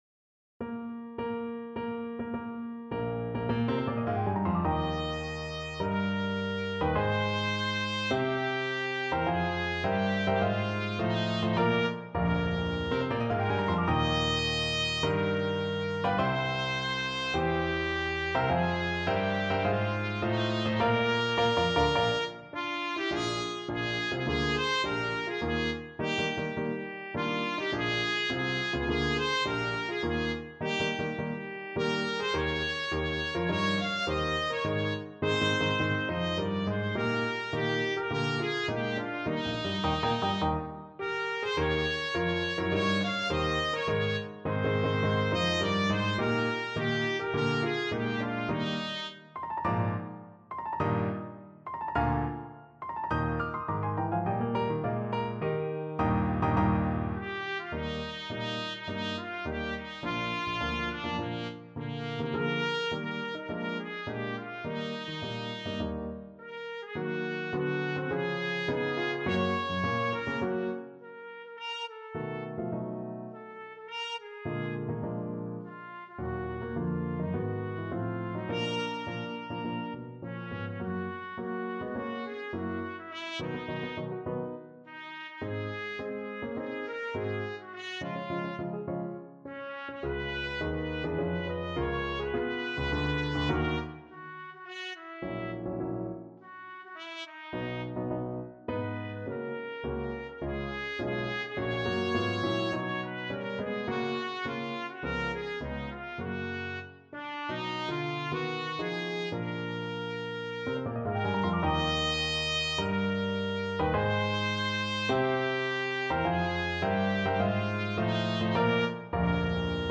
Trumpet version
4/4 (View more 4/4 Music)
Marziale-Energico =104
Traditional (View more Traditional Trumpet Music)